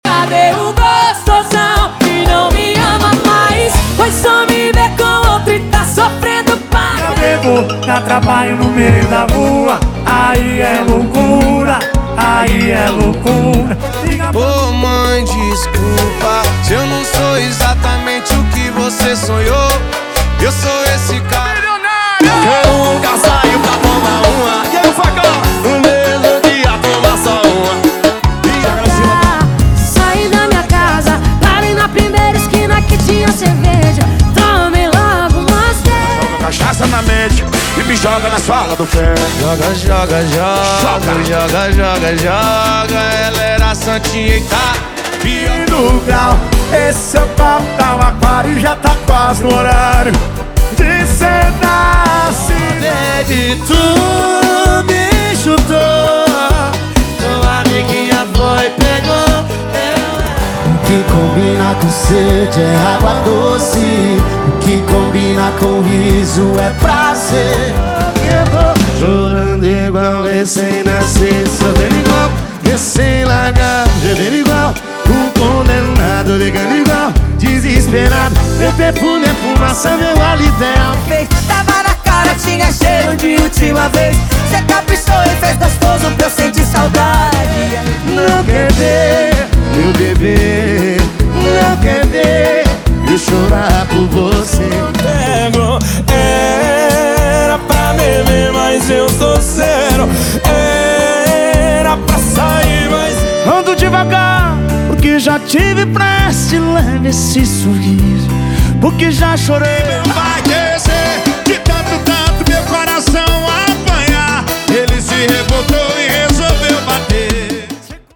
Os melhores sertanejos atualizados estão aqui!
Sem Vinhetas